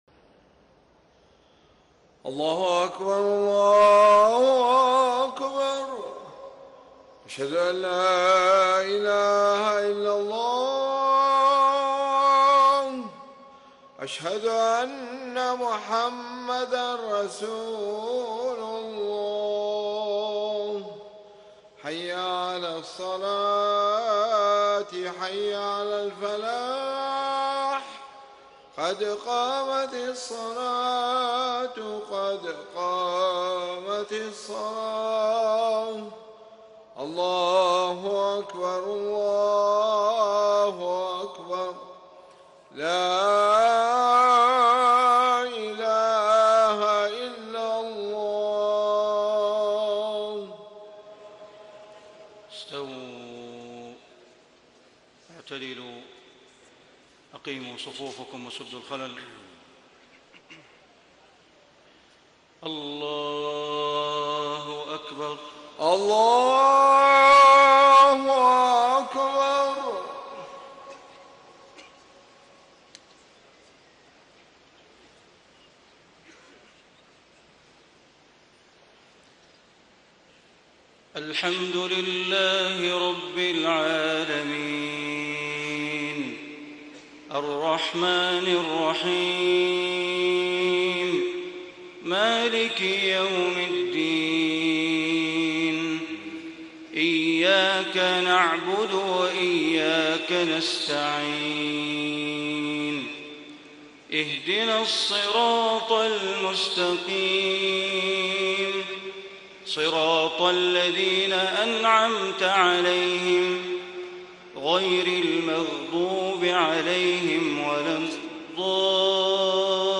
صلاة المغرب 4 - 1 - 1435هـ آخر سورتي الفجر و الليل > 1435 🕋 > الفروض - تلاوات الحرمين